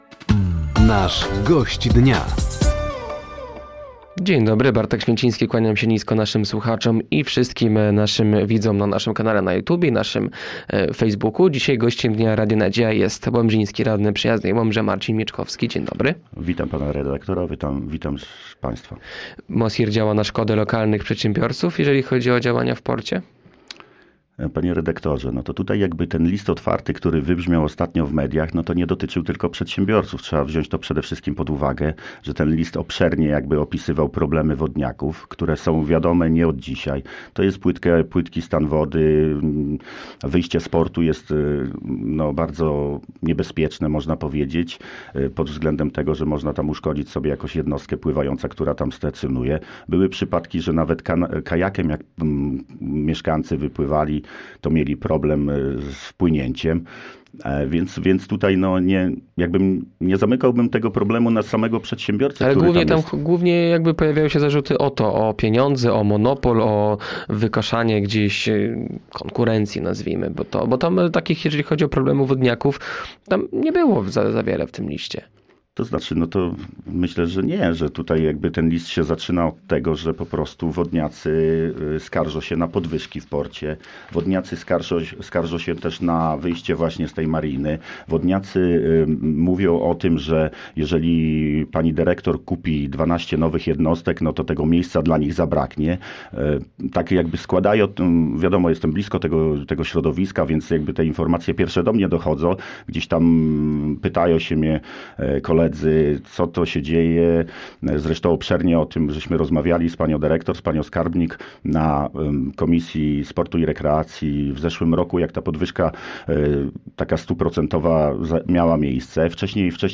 Gościem Dnia Radia Nadzieja był łomżyński radny Przyjaznej Łomży Marcin Mieczkowski. Głównym tematem rozmowy był list wodniaków do władz miasta w sprawie działań MOSiR-u w porcie.